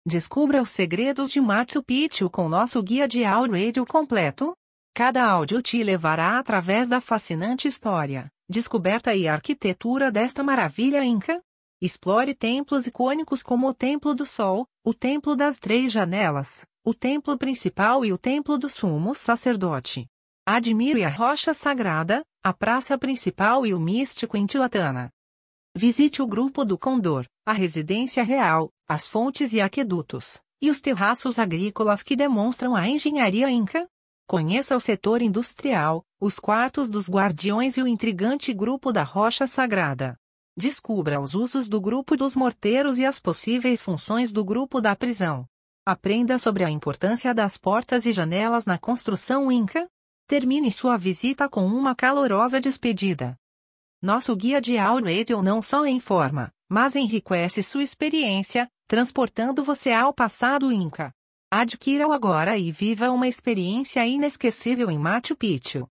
Audioguia de Machu Picchu em Português
O Audioguia de Machu Picchu oferece uma experiência imersiva com 26 áudios cuidadosamente narrados. Desde a recepção até a despedida, cada áudio irá guiá-lo pelos pontos mais importantes da antiga cidade inca, como o Templo do Sol, a Casa do Guardião, o Intihuatana e muito mais.